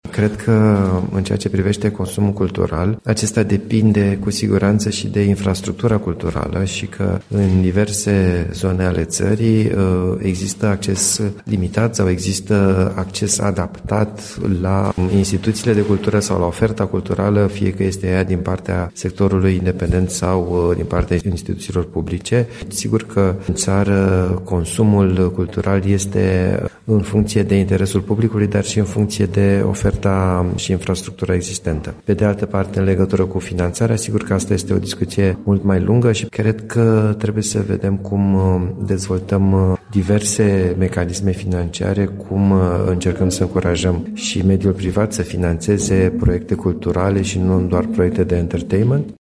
Acest subiect a fost dezbătut, astăzi, la Ipotești, în cadrul Zilelor Eminescu.
La eveniment, a luat parte inclusiv președintele Institutului Cultural Român, Liviu Sebastian Jicman, care a vorbit despre finanțarea și consumul cultural din România: